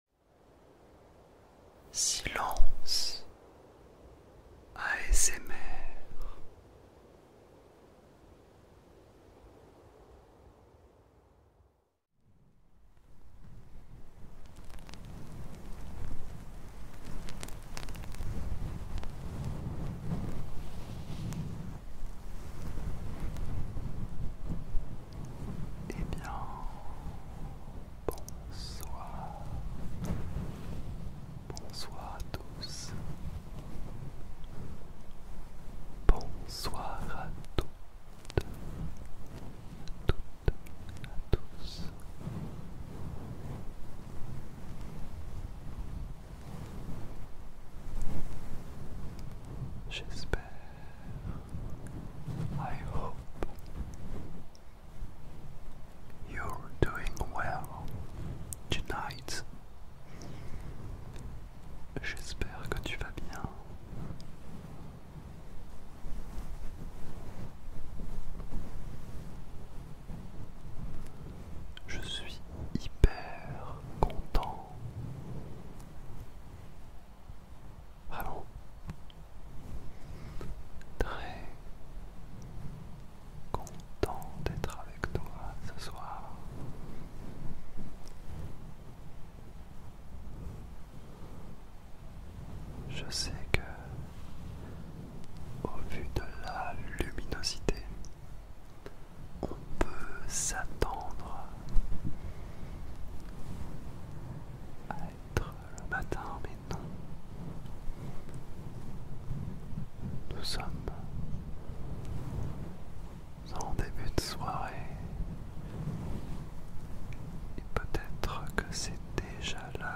Expérimente l'ASMR !
ASMR-10-MINUTES-pour-tendormir-fais-le-test-.mp3